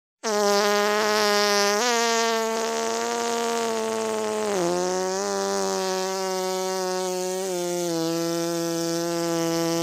Boo Fart Sound Button - Free Download & Play